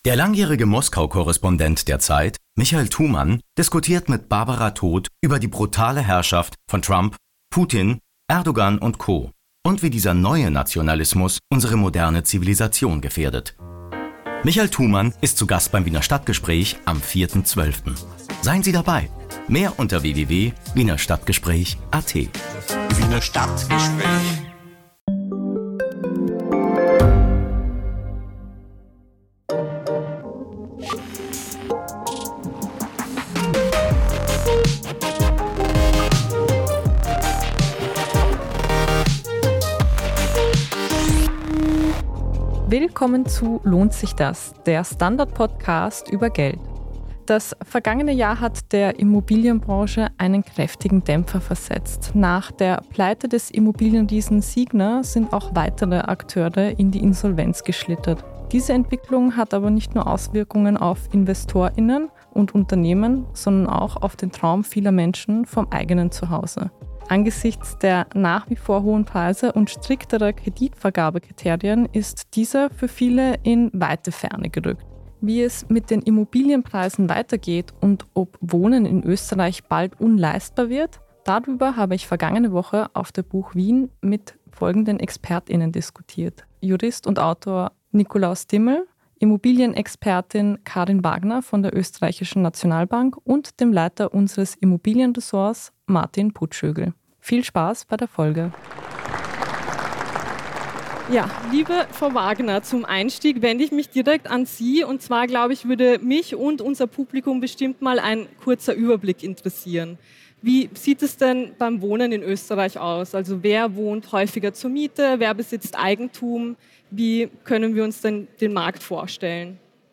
Aber auch die gestiegenen Mieten machen vielen Menschen zu schaffen. Auf der Buch Wien 2024